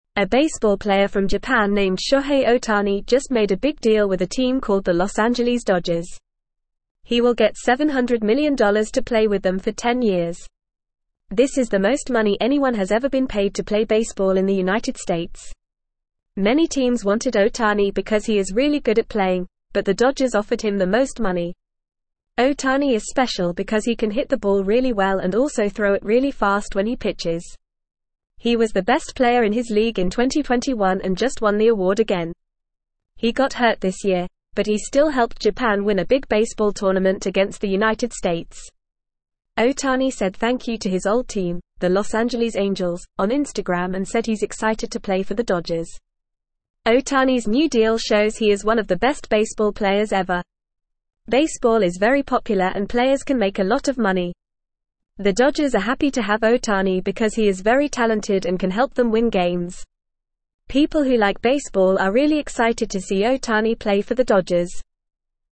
Fast
English-Newsroom-Lower-Intermediate-FAST-Reading-Japanese-Baseball-Star-Signs-Big-Deal-with-Los-Angeles.mp3